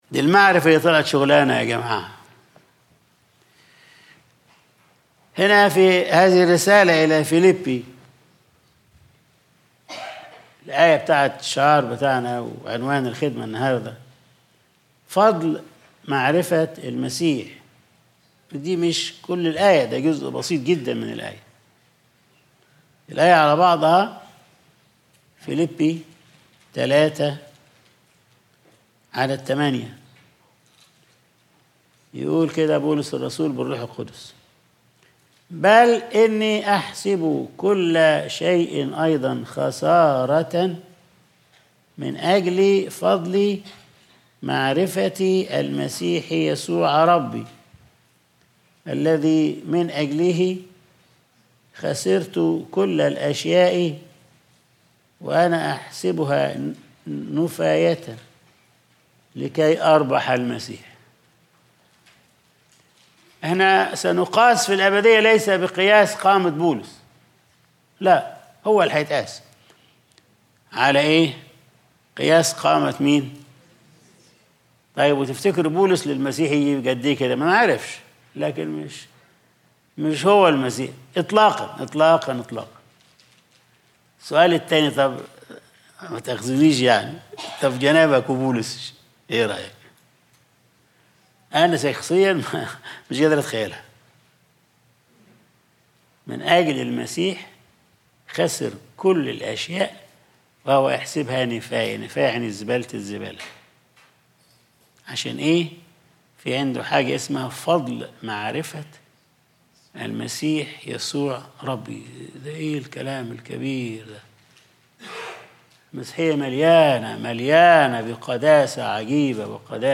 Sunday Service | فضل معرفة المسيح